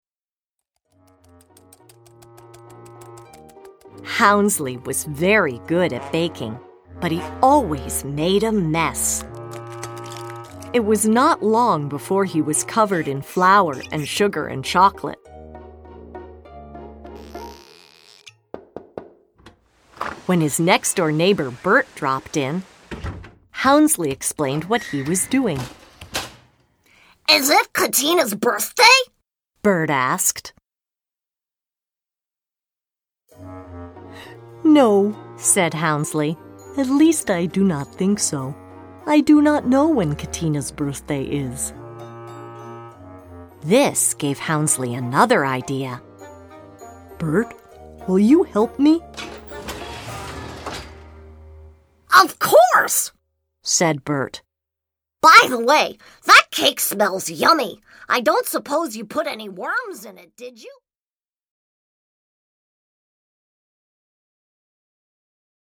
Readalongs